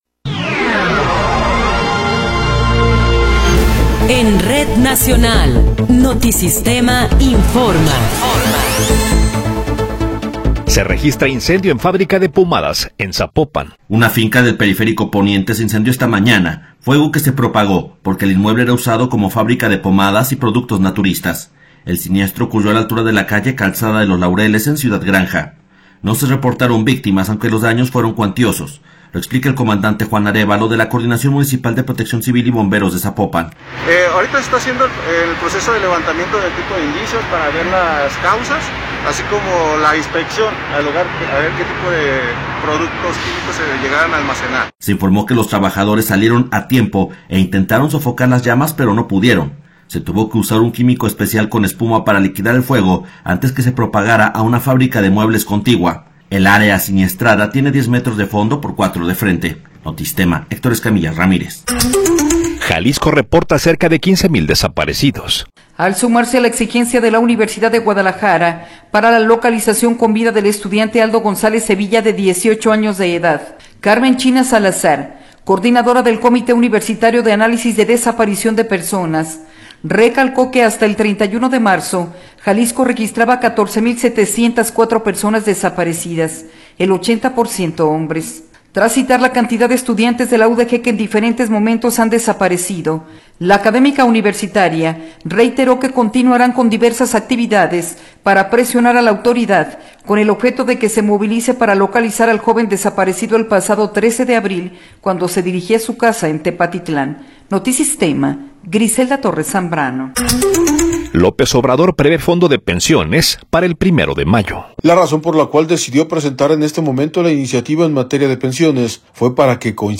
Noticiero 11 hrs. – 19 de Abril de 2024
Resumen informativo Notisistema, la mejor y más completa información cada hora en la hora.